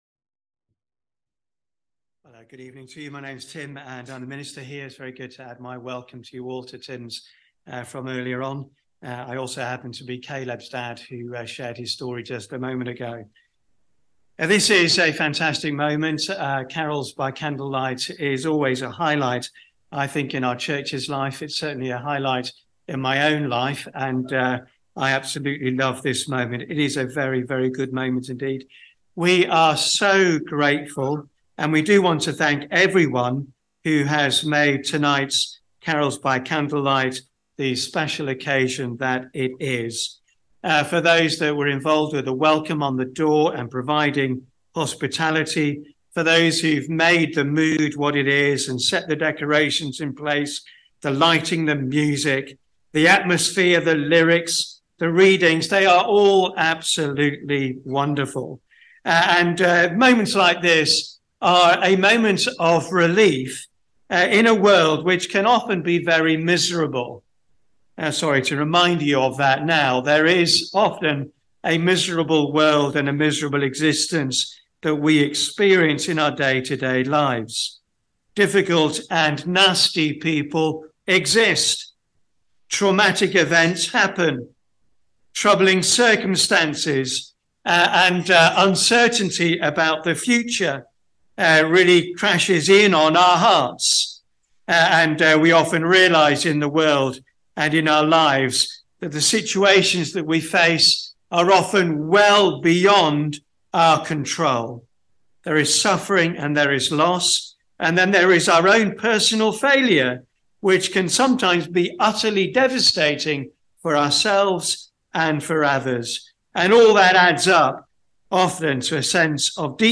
Bible Talks | Christ Church Central | Sheffield
Holiday Club - All Age Service